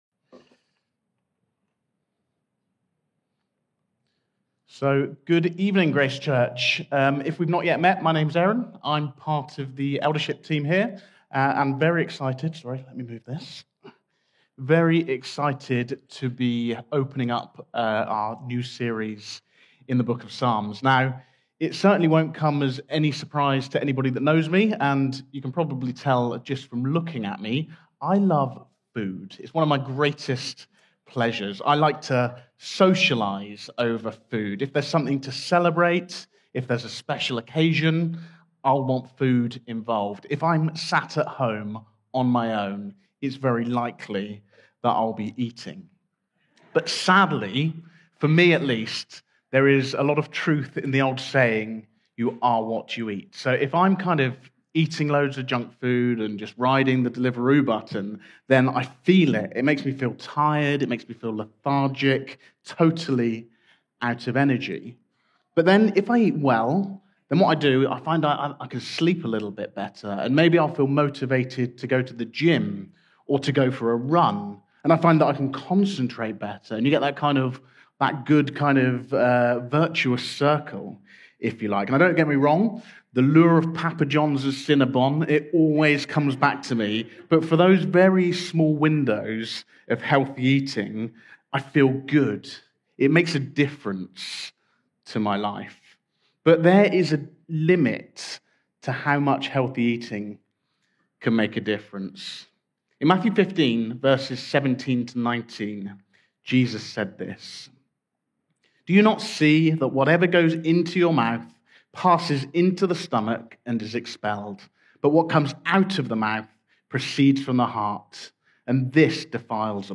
This sermon explores what it means to delight in God's Word, remain firmly rooted in Him, and avoid influences that lead us away from His truth.